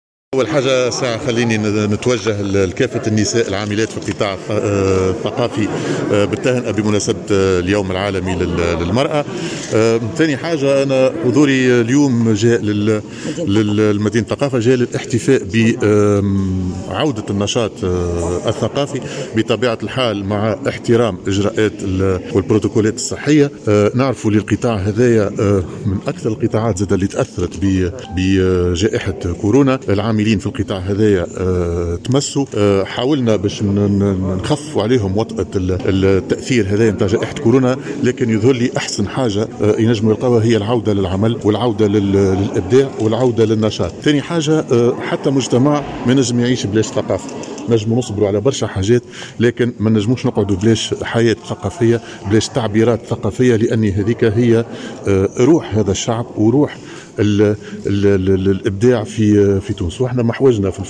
أعلن رئيس الحكومة هشام المشيشي في تصريح لمراسلة الجوهرة "اف ام" مساء اليوم الأربعاء ,من مقر مدينة الثقافة عن استئناف الأنشطة الثقافية مشددا على أهمية دور الثقافة في المجتمعات.